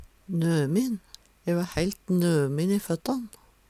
nømin - Numedalsmål (en-US)